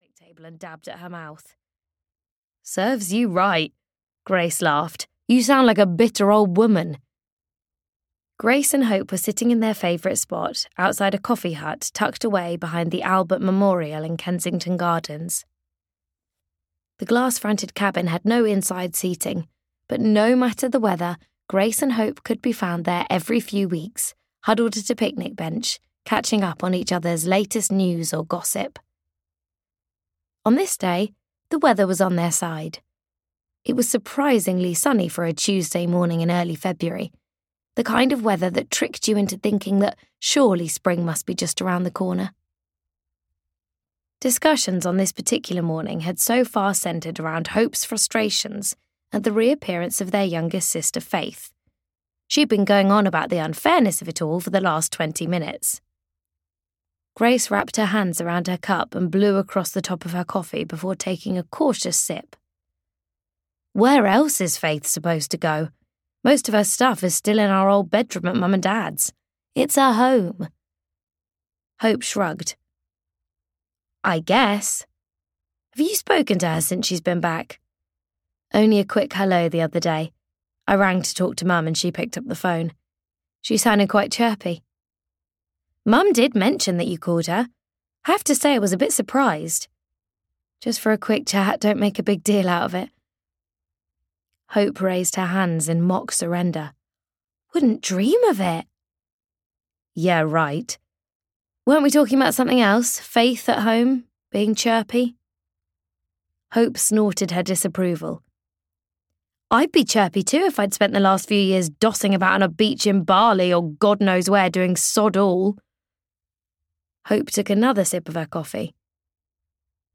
The Little Shop on Floral Street (EN) audiokniha
Ukázka z knihy